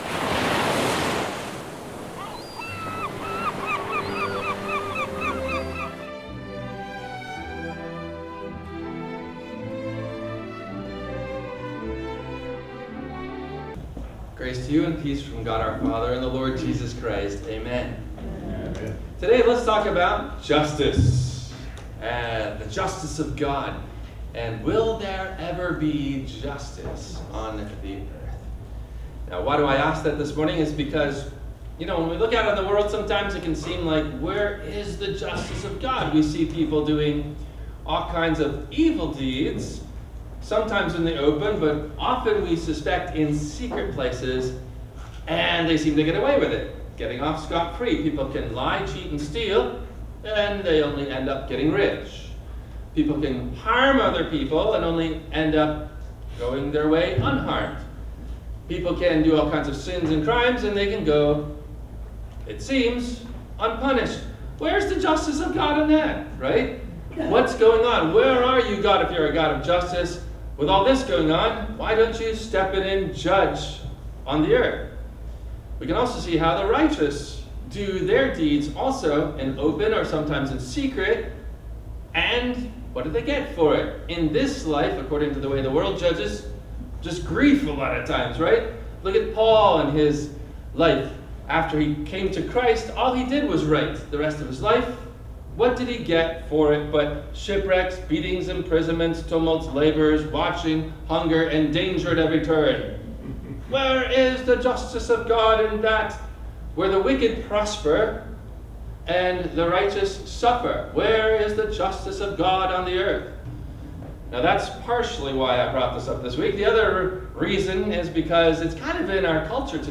Will There Ever be … Justice on Earth? – WMIE Radio Sermon – August 04 2025